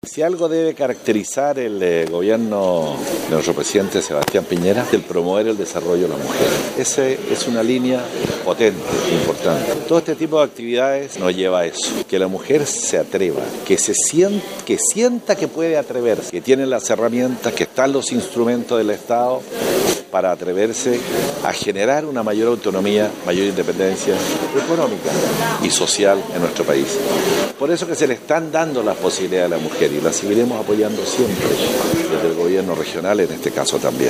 El Intendente, Harry Jürgensen explicó que lo caracteriza al Gobierno del Presidente Sebastián Piñera es la promoción del desarrollo de la mujer, donde este tipo de actividades fomentan a que la mujer se atreva a emprender, ya que existen los instrumentos del estado para generar su autonomía.